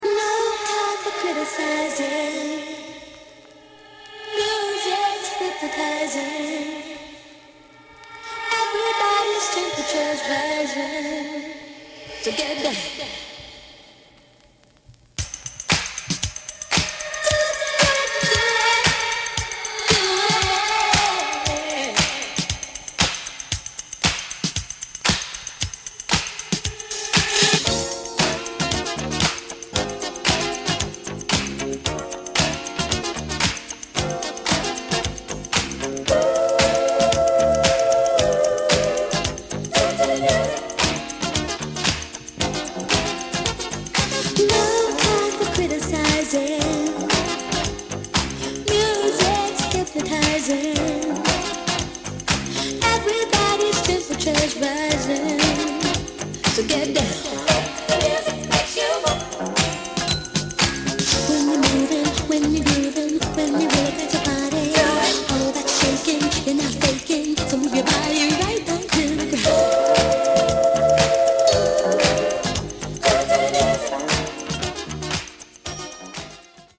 Contains the dub.